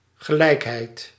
Ääntäminen
IPA : /ˈsɛm.bləns/